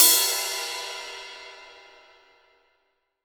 Index of /90_sSampleCDs/AKAI S6000 CD-ROM - Volume 3/Crash_Cymbal1/16-17_INCH_CRASH